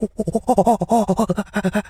Animal_Impersonations
monkey_chatter_10.wav